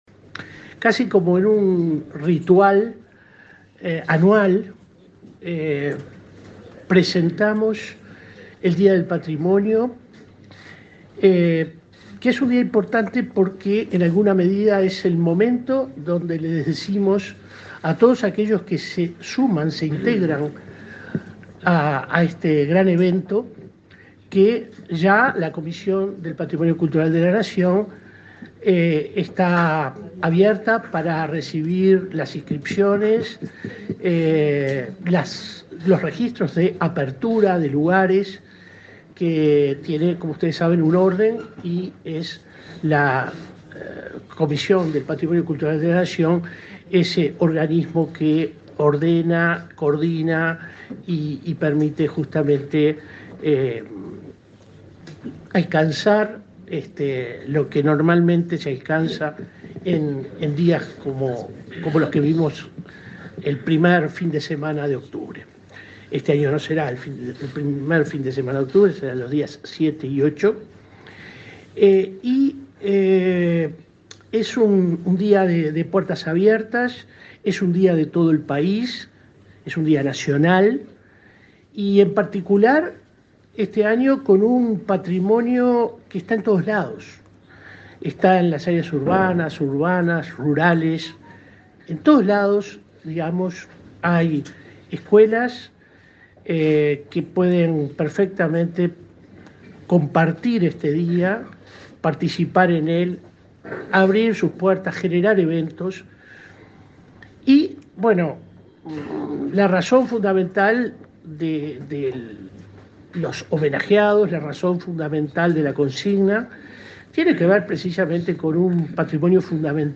Palabras de autoridades del MEC
Palabras de autoridades del MEC 26/04/2023 Compartir Facebook X Copiar enlace WhatsApp LinkedIn El Ministerio de Educación y Cultura (MEC) realizó la presentación del Día del Patrimonio 2023. El director de la Comisión de Patrimonio Cultural de la Nación, William Rey, y el titular de la citada cartera, Pablo da Silveira, destacaron las características del evento, que se llevará a cabo los días 7 y 8 de octubre.